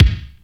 SINGLE HITS 0006.wav